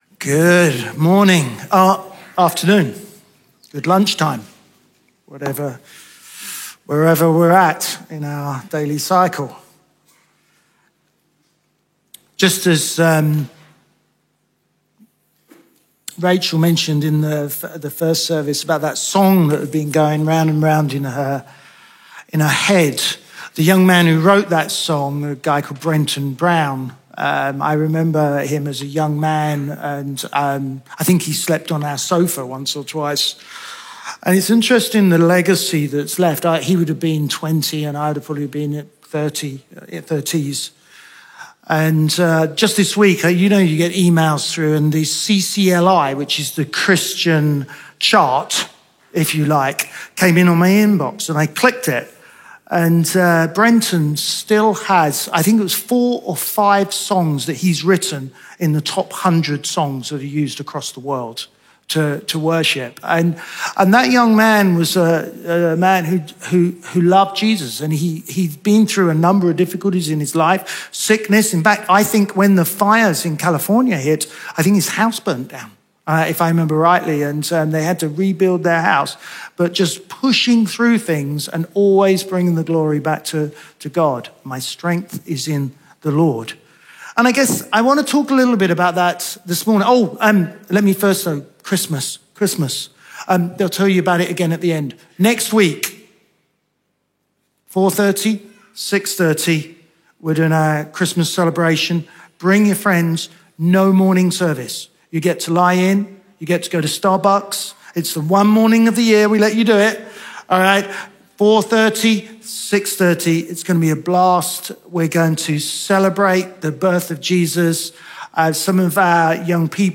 Chroma Church - Sunday Sermon Motives, New Heart and Love of God Dec 05 2022 | 00:31:42 1x 00:00 / 00:31:42 Subscribe Share RSS Feed Share Link Embed